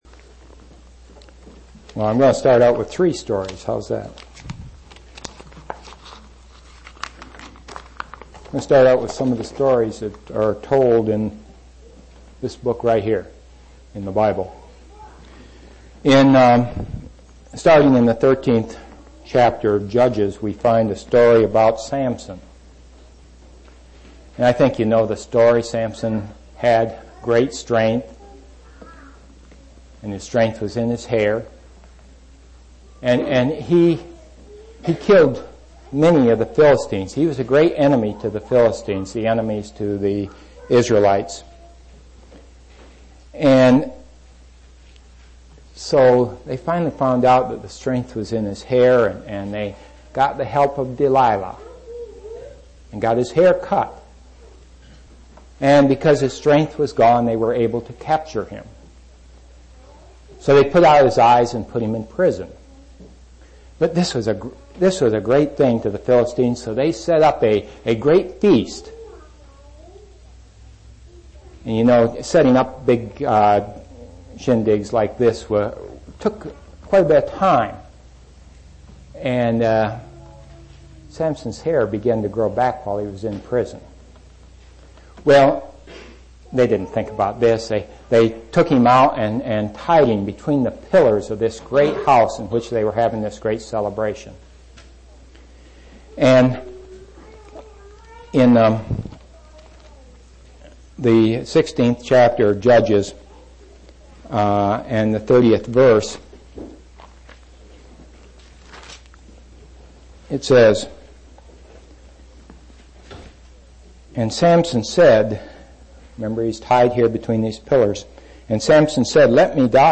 9/20/1992 Location: East Independence Local Event